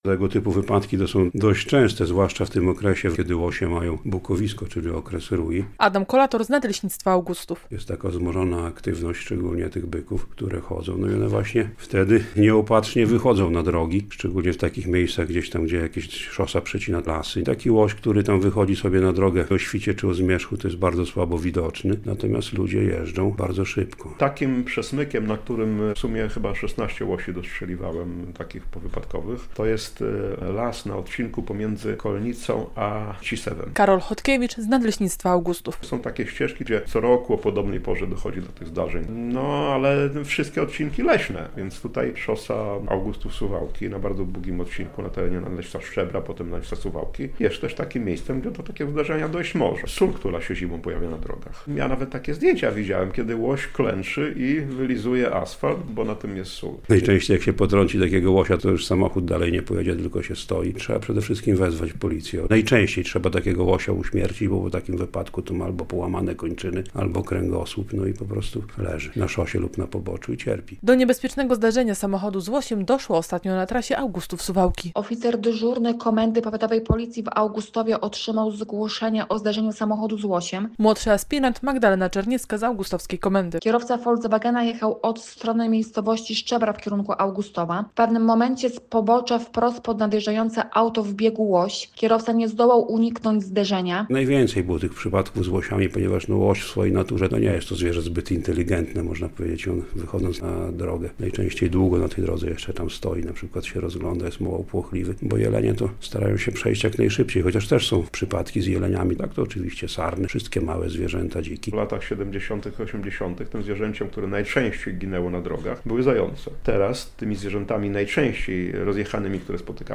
Jesienią rośnie liczba wypadków z udziałem zwierząt - relacja